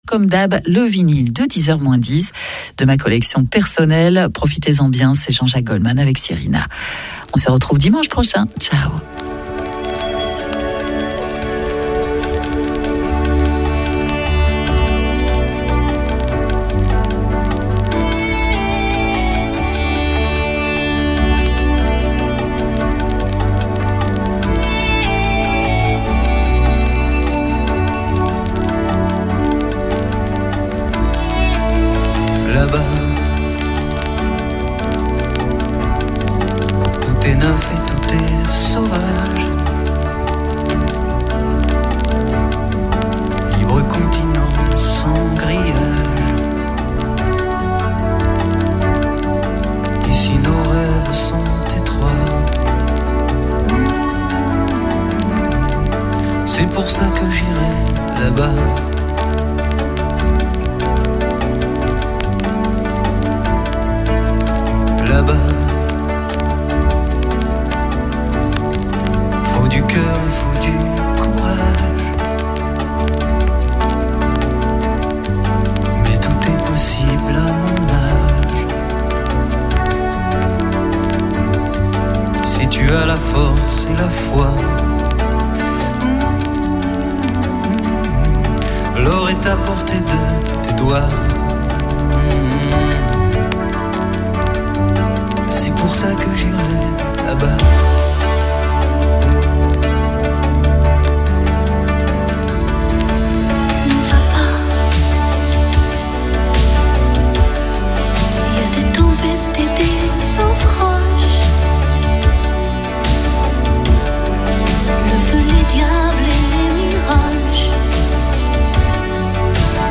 🎧 Un dialogue musical devenu légendaire
Un échange entre deux voix, deux visions du monde :